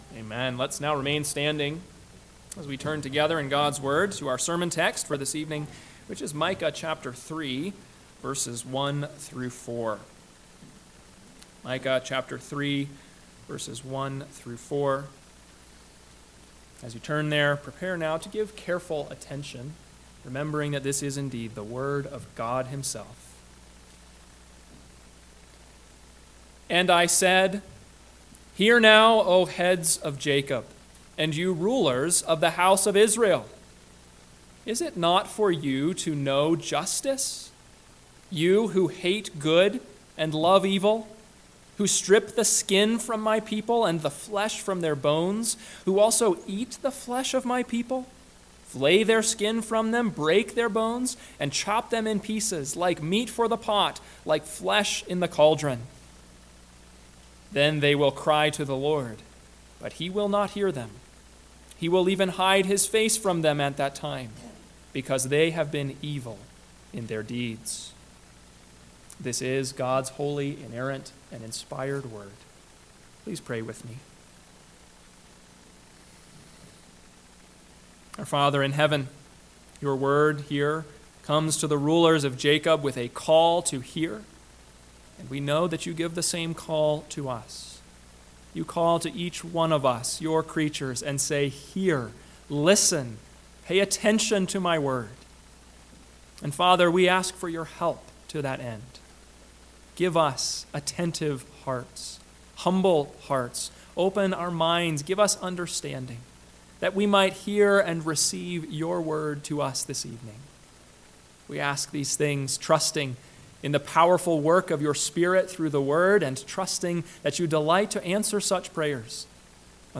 PM Sermon – 9/22/2024 – Micah 3:1-4 – Northwoods Sermons